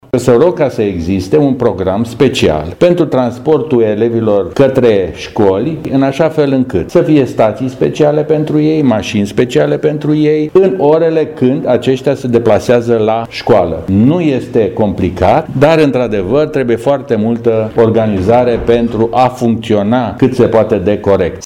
Senatorul PNL de Brașov, Nicolae Vlad Popa, a precizat într-o conferință de presă că va solicita Primăriei Brașov organizarea unor linii speciale ale Regiei Autonome de Transport, pentru transportul elevilor din ciclul de învățământ primar, către și de la unitățile de învățământ, odată cu debutul noului an școlar: